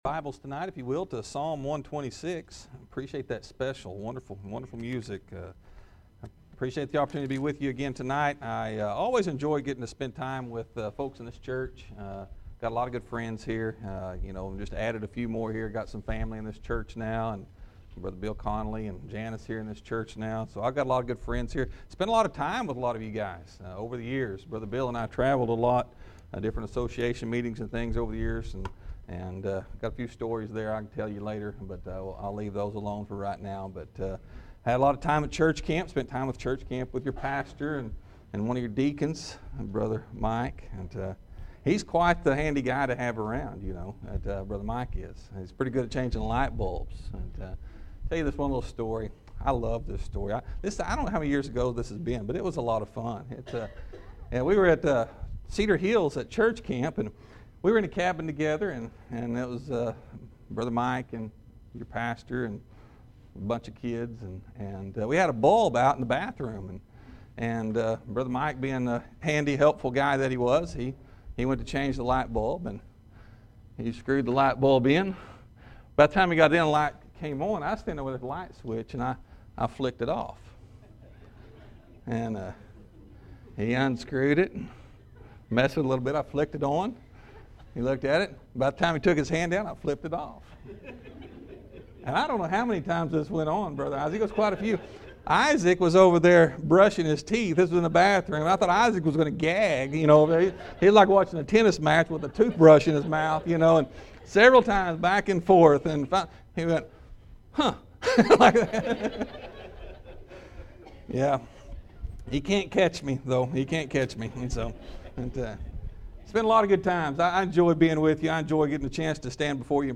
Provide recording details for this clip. Fall Revival 2017 Night 2-Precious Seed and Powerful Promises